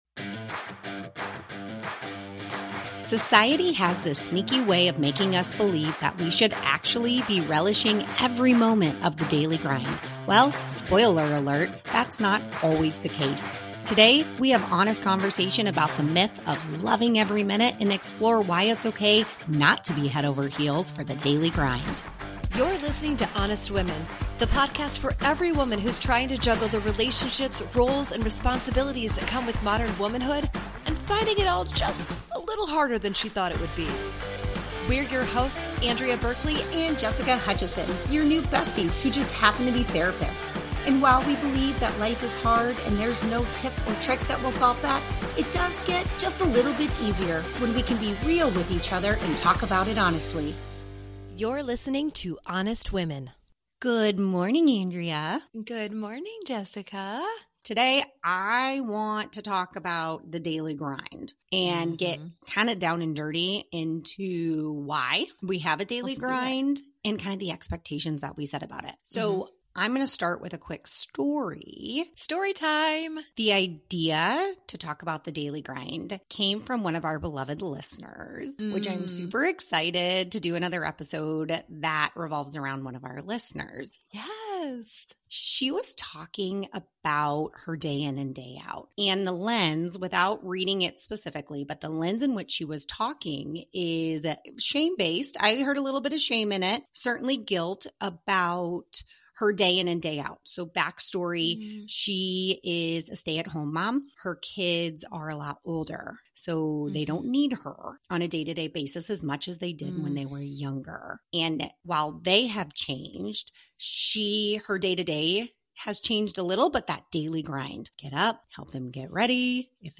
Today, we have an honest conversation about the myth of "loving every minute" and explore why it's okay not to be head over heels for the daily grind.